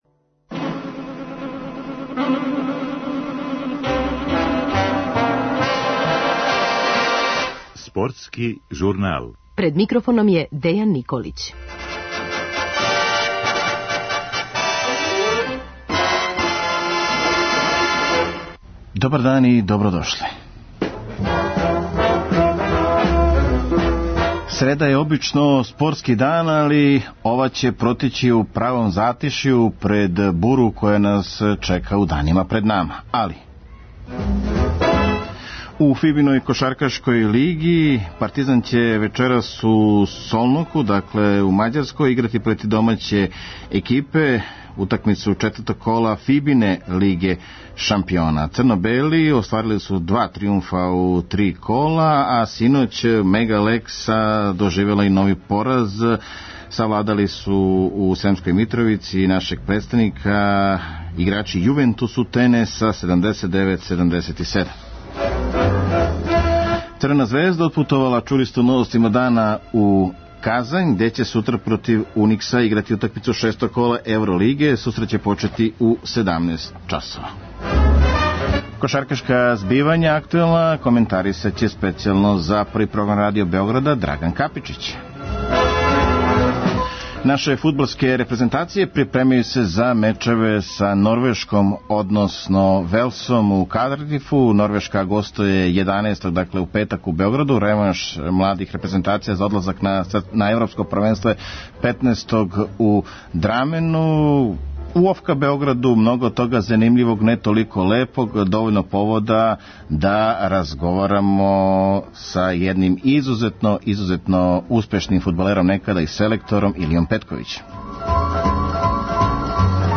Бавићемо се актуелним стањем у ОФК Београду, гост емисије легендарни Илија Петковић.